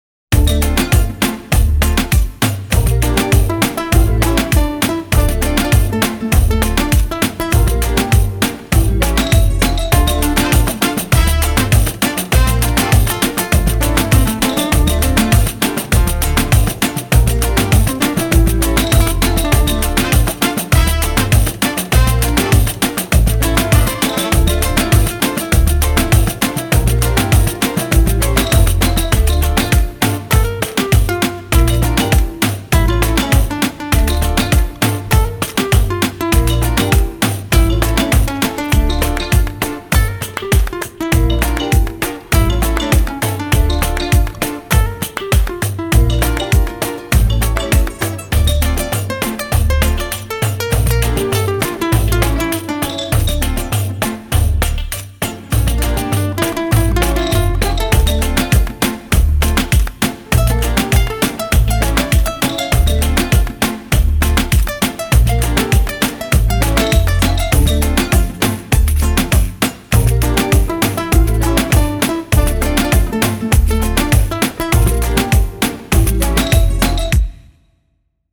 拉丁鼓与巴西鼓
这套涵盖了地道南美节奏的庞大音源库，充满了仿佛沐浴在阳光下的充满活力的律动。
收录乐器：潘代罗鼓、哈罗乔潘代罗鼓、雷皮尼克鼓、苏尔多鼓、坦博林鼓和廷巴莱斯鼓、木琴和铃铛。
Pandeiro_Demo.mp3